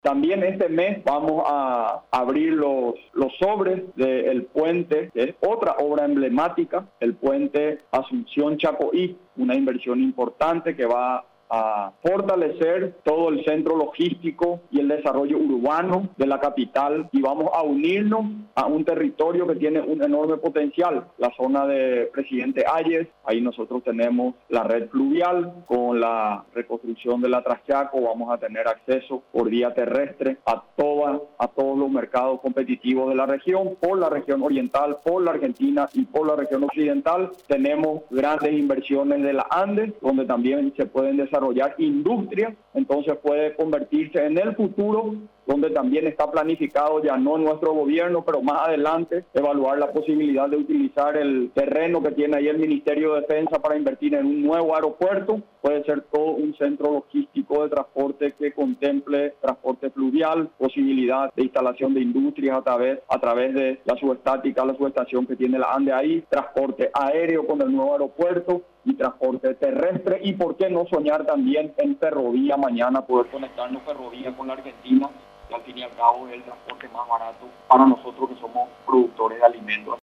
Con la finalidad de unir las regiones oriental y occidental, se proyecta la construcción de un puente en el trayecto Asunción – Chaco´í, informó el presidente de la República, Mario Abdo Benítez.